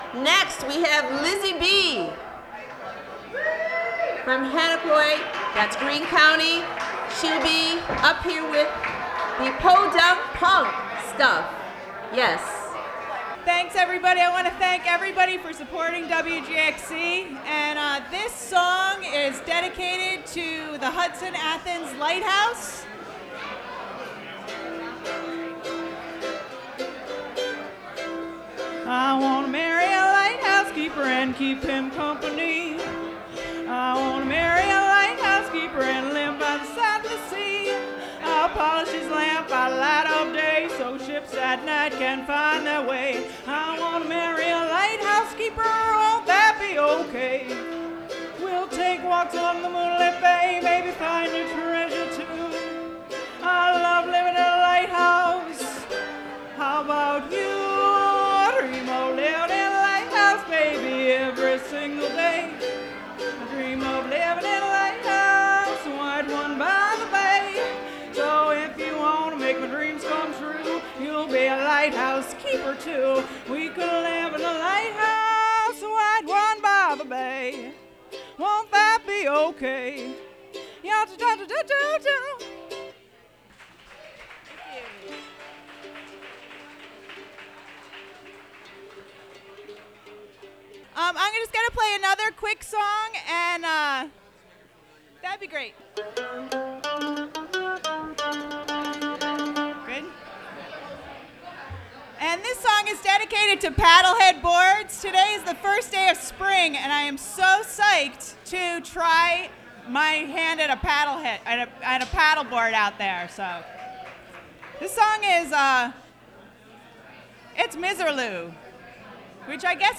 performs at WGXC 90.7-FM Spring Forward Crossroads Open Mic
Recorded from WGXC 90.7-FM webstream.